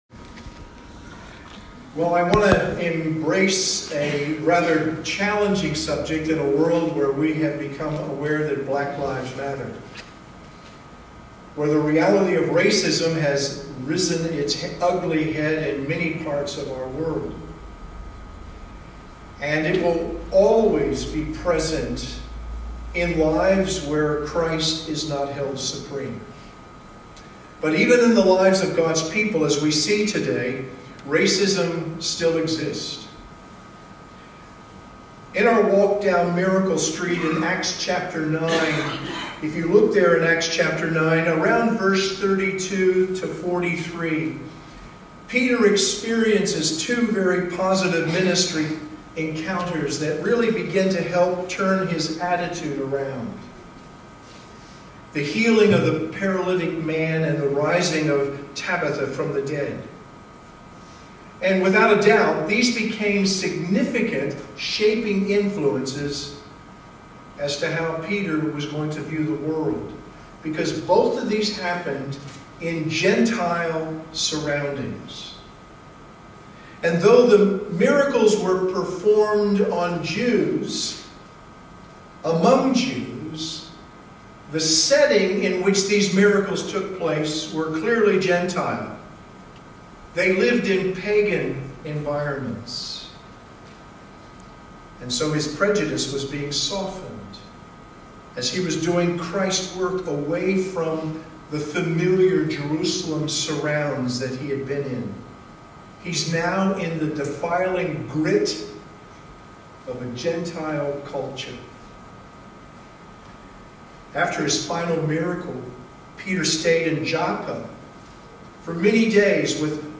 Bible Text: Acts 10: 1-23 | Preacher